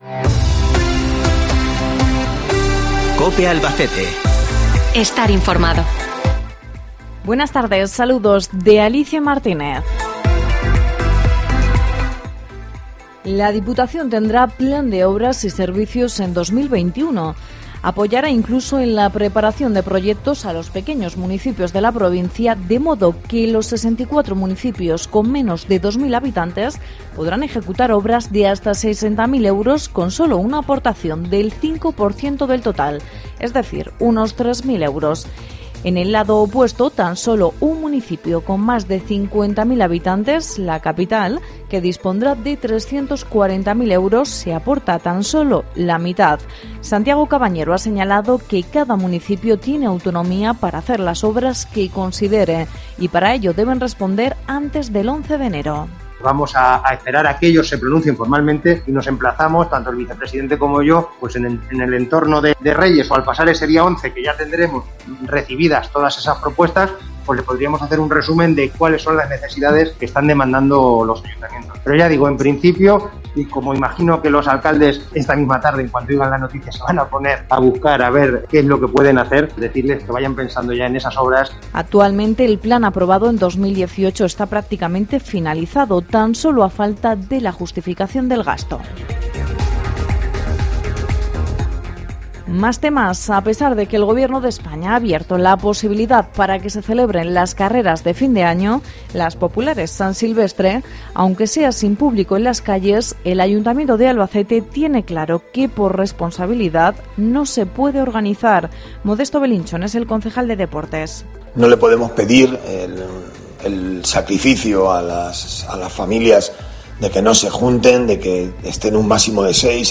Informativo Mediodia Jueves 26 de Noviembre